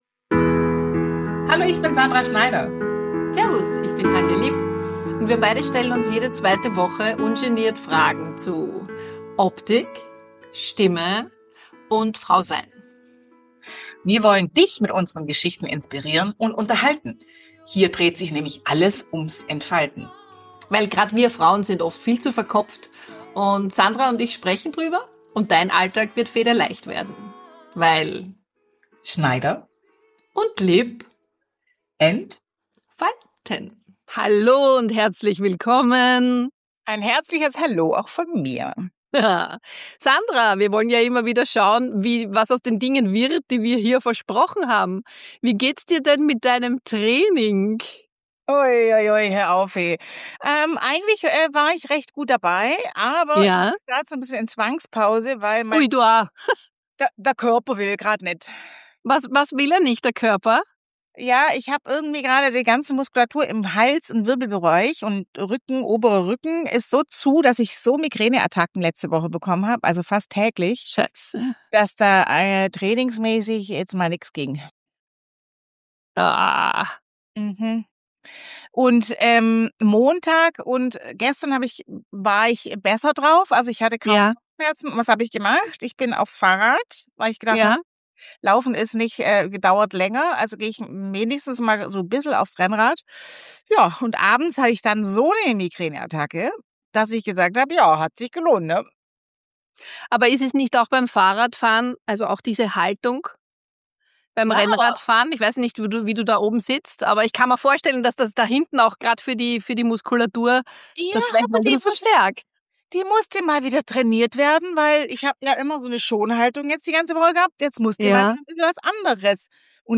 Nur zwei Frauen, die ehrlich darüber reden, was passiert, wenn man nicht mehr kann und was dabei manchmal unerwartet entsteht.